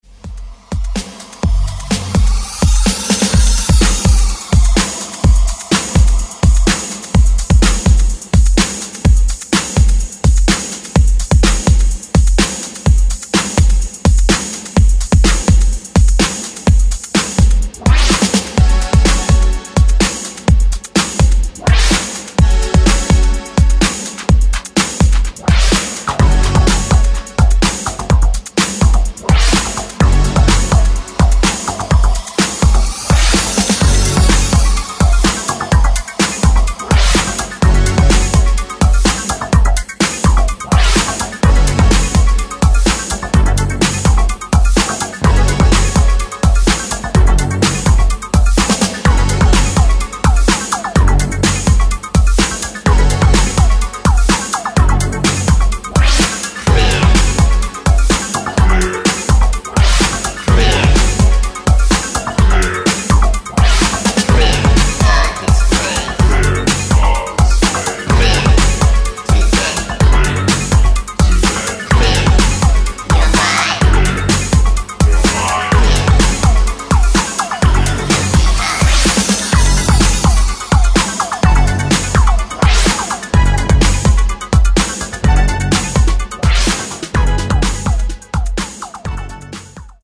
Genres: EDM , TOP40
Clean BPM: 130 Time